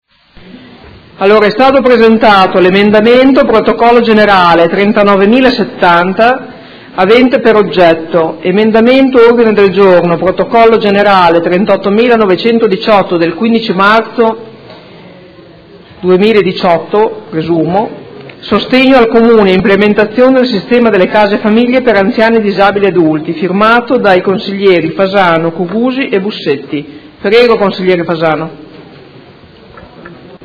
Seduta del 15/03/2018. Legge emendamento a mozione su proposta di deliberazione: Approvazione del Regolamento per la Disciplina delle Case famiglia per anziani e disabili adulti